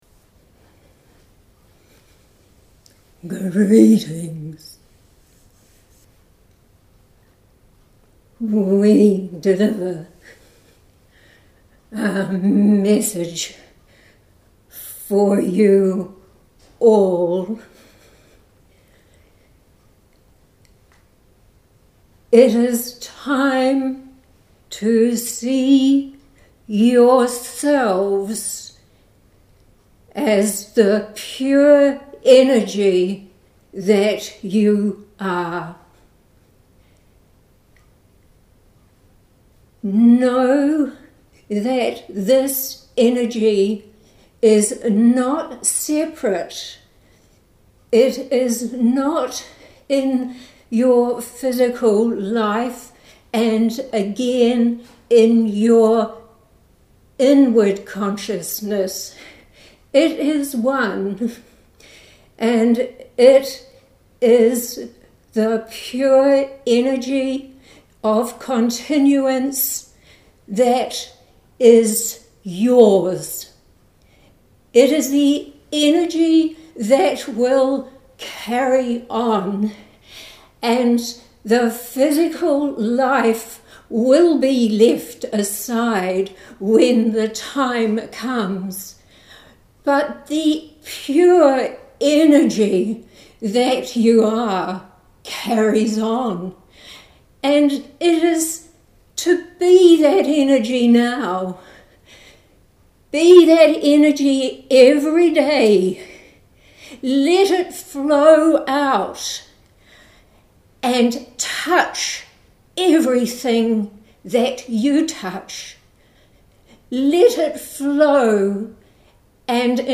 Posted in Audio recording, Channelled messages, Metaphysical, Spirituality, Trance medium